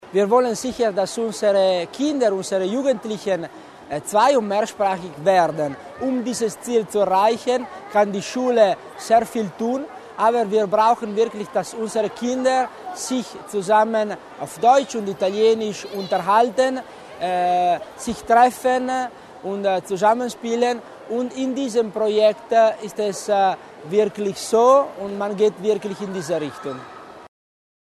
Landesrat Tommasini zur Bedeutung des Projektes für die Kinder